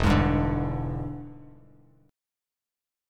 E7#9 chord